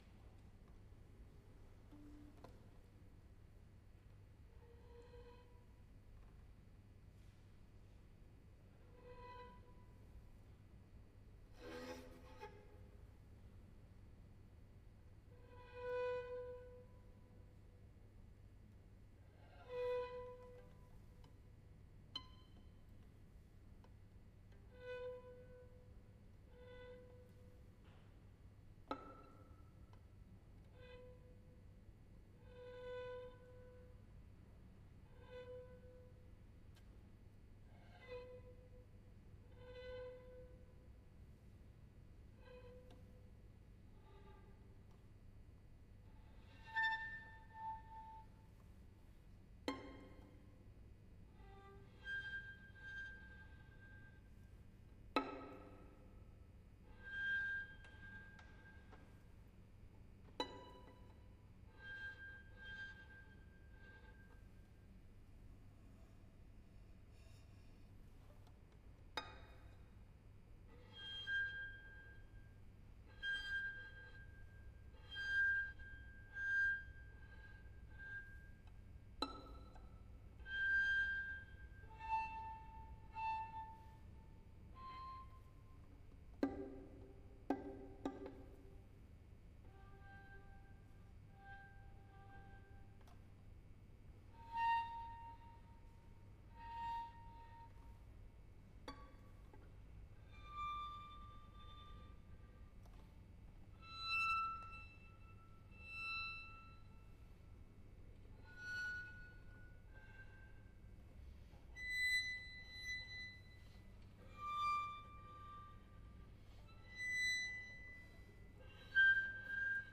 Live performance. London 2007